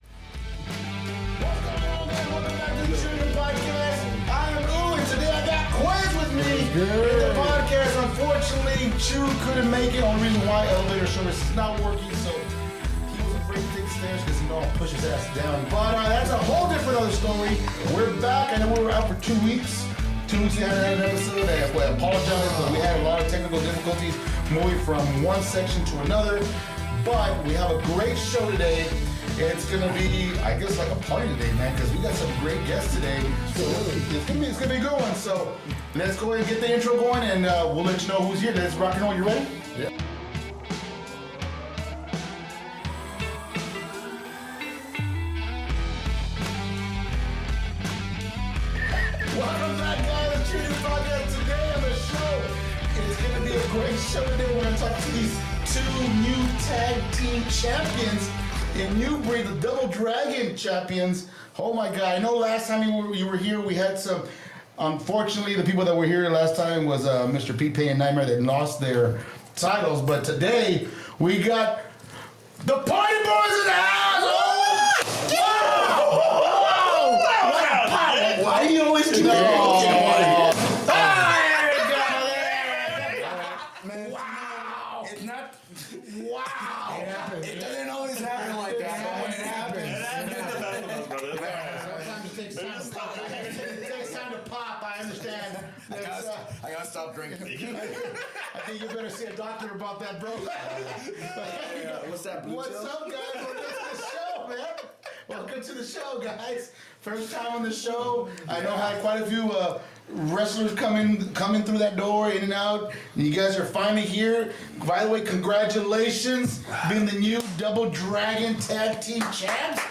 Join us for a thrilling and entertaining ride as we bring you exclusive interviews with local talent, businesses, artists, actors, and directors from the RGV 956.